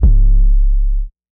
SOUTHSIDE_808_biggie_biggs_C#.wav